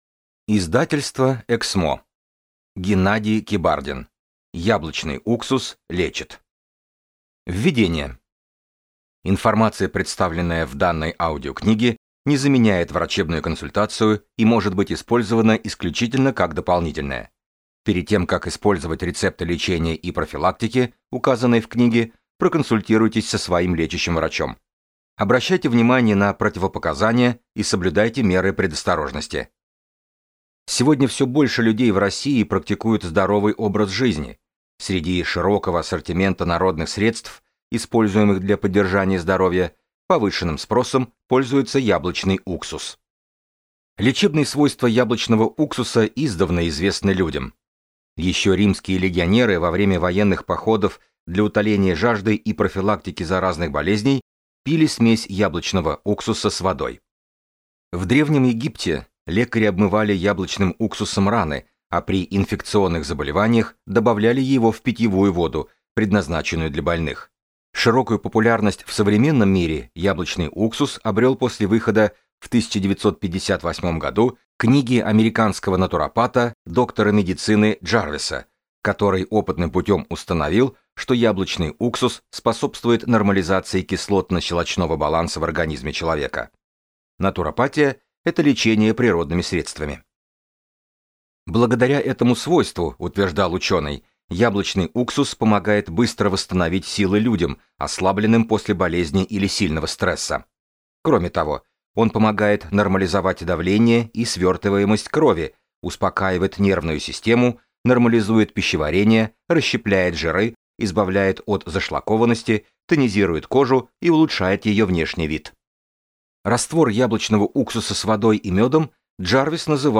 Аудиокнига Яблочный уксус лечит | Библиотека аудиокниг